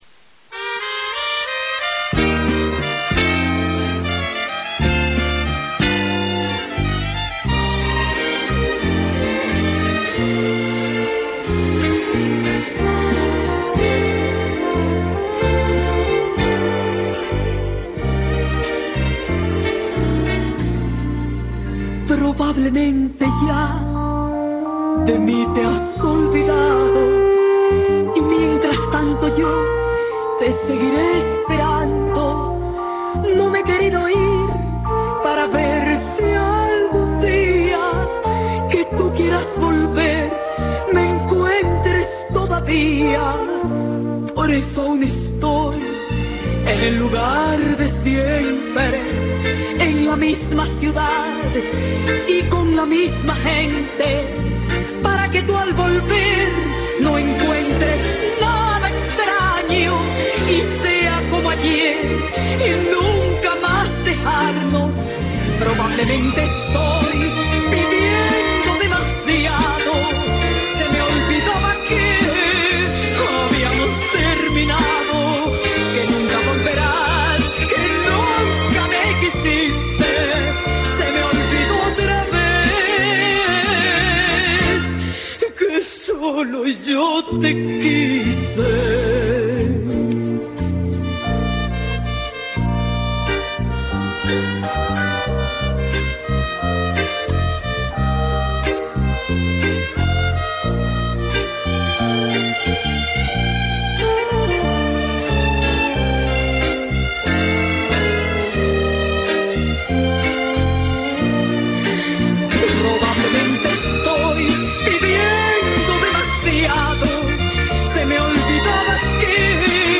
Balada